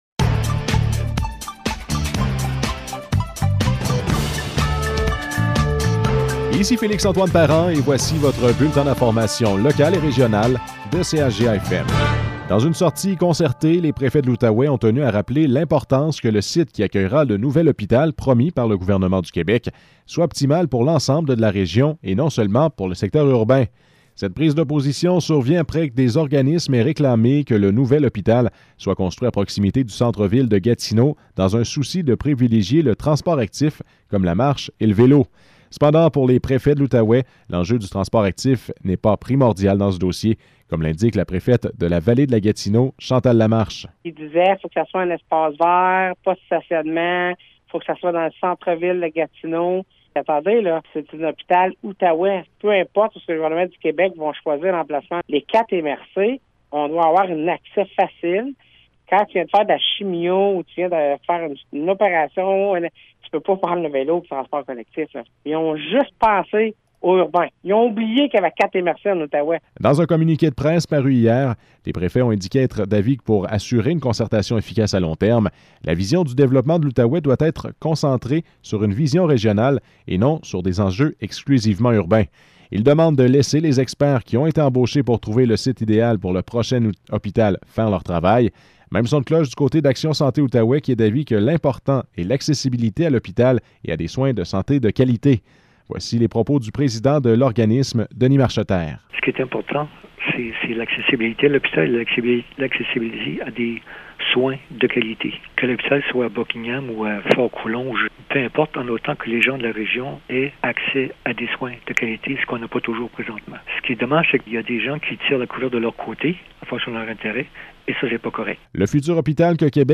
Nouvelles locales - 2 décembre 2021 - 12 h